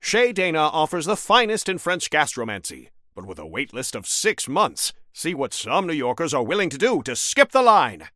Newscaster_headline_71.mp3